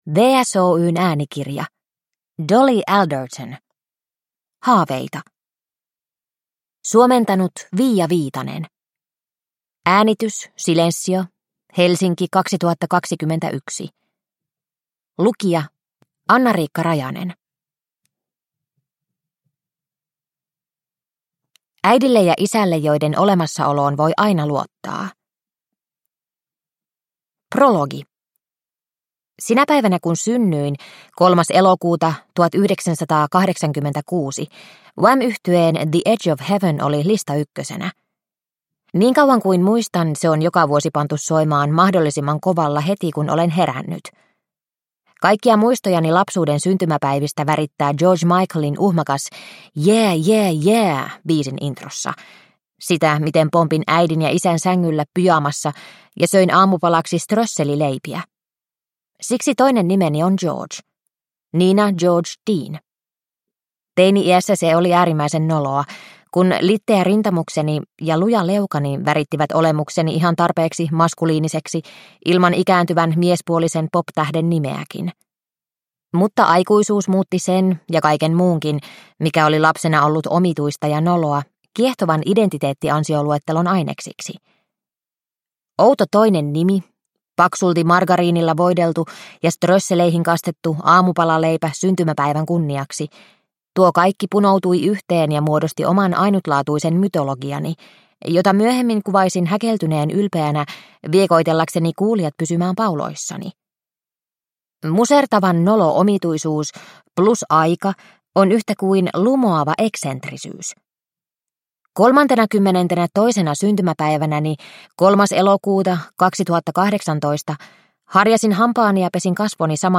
Haaveita – Ljudbok – Laddas ner